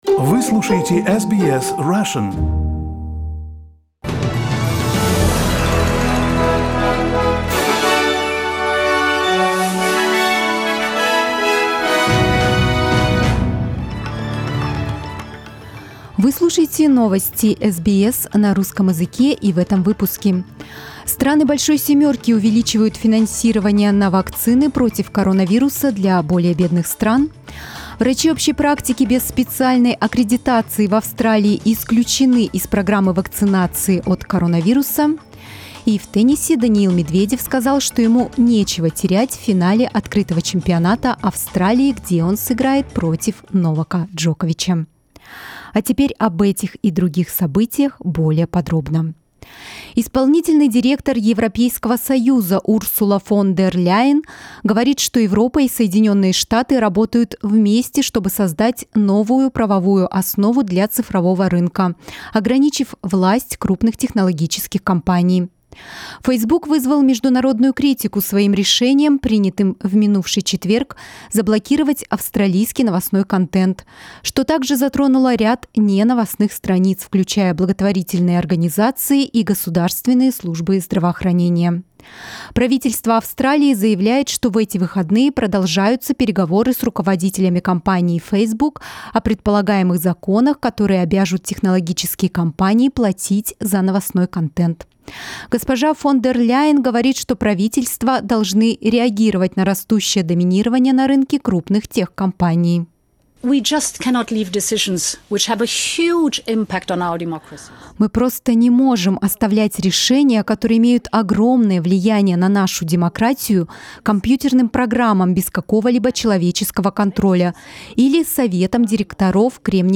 Новостной выпуск за 20 февраля